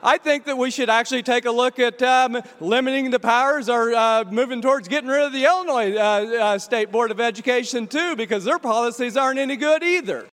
Republican state Rep. Blaine Wilhour of Beecher City said on the House floor in Springfield Thursday it was time to get rid of more than just the federal education department.